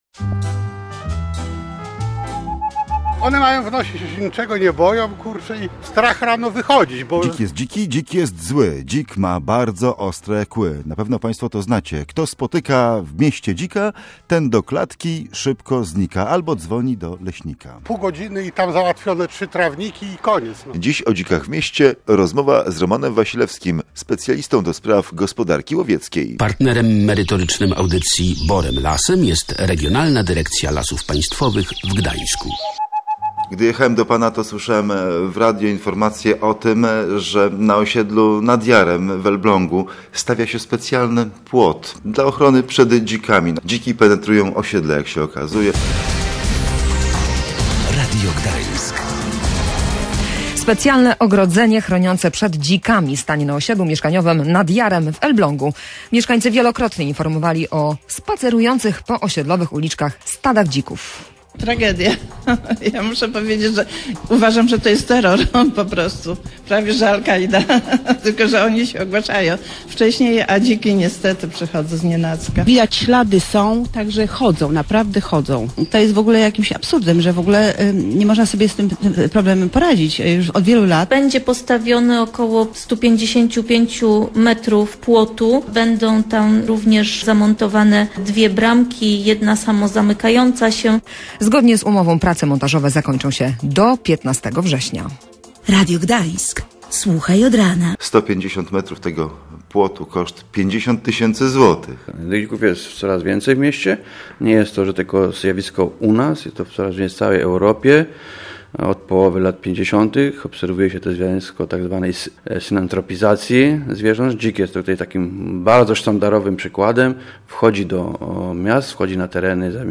Posłuchaj audycji o dzikach:/audio/dok1/boremlasem-dziki.mp3